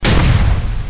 scattergun-turret-fire.ogg